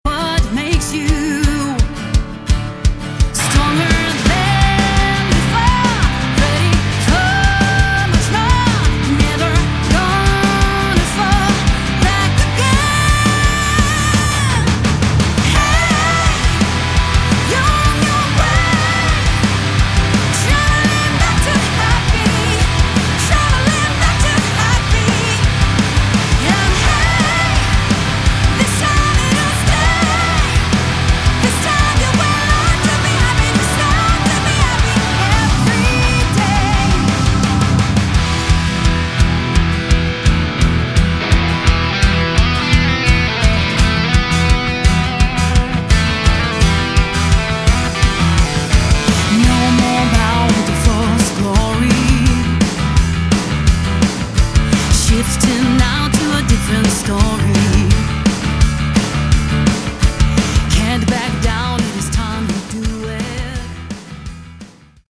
Category: Melodic Metal
vocals
guitars
bass
keyboards
drums